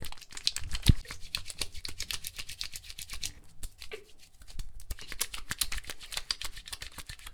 Lavándose frotando con agua y jabón
Me gusta Descripción Grabación sonora en la que se escucha el sonido de alguien lavándose, realizando higiene personal, frotandose con sus manos con agua y jabón. Sonidos cotidianos